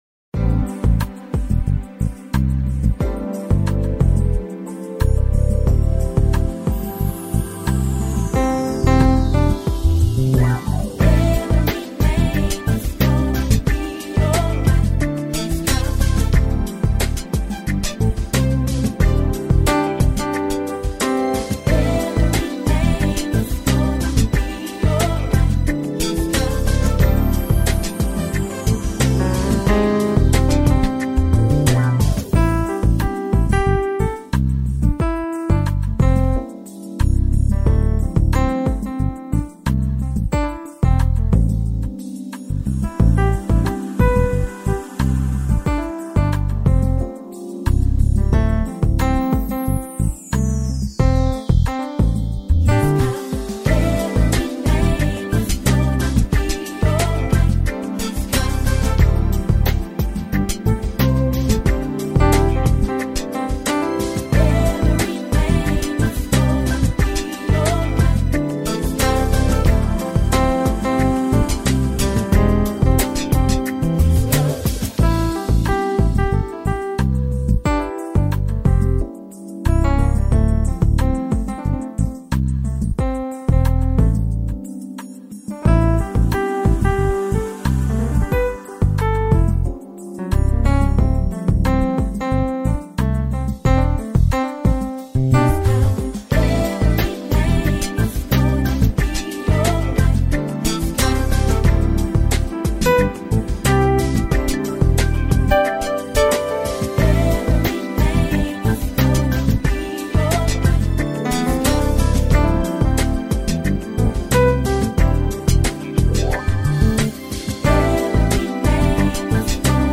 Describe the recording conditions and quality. Click to listen or download a recording of this live show.